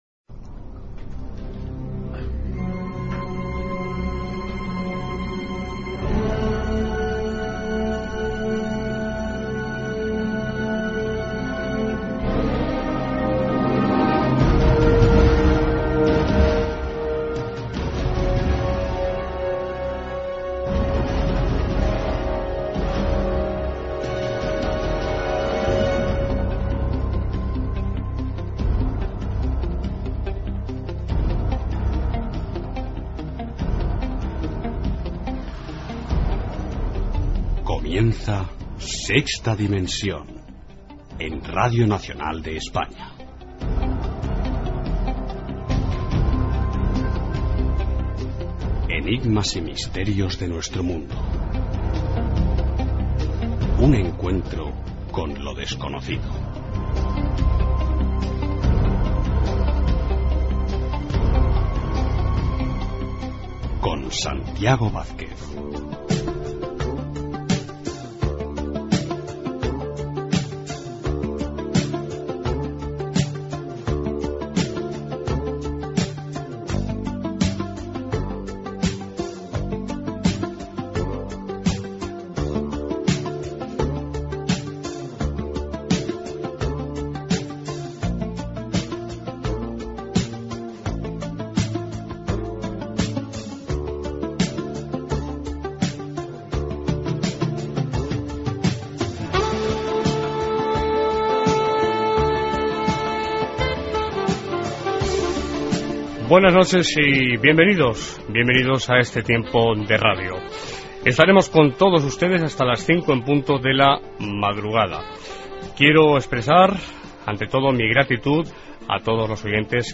Careta del programa, presentació, equip, indicatiu del programa, adreces de la web i del correu electrònic, indicatiu del programa, hora
Divulgació